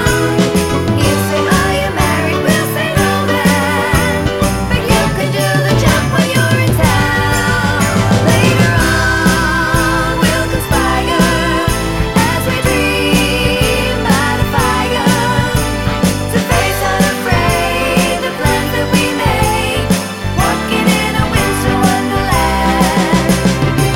One Semitone Down Christmas 2:14 Buy £1.50